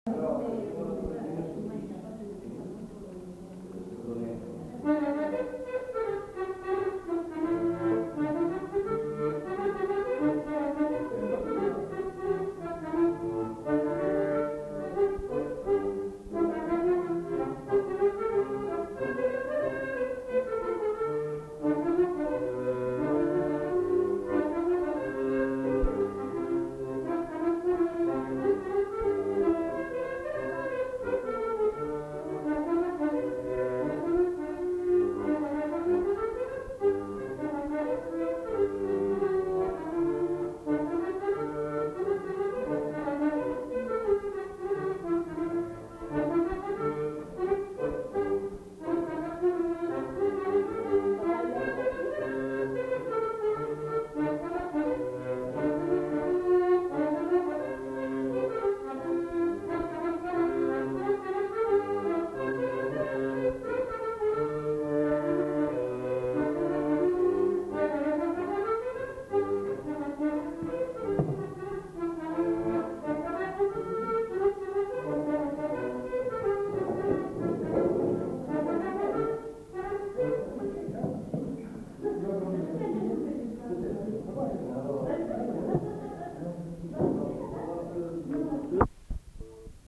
enquêtes sonores
Scottish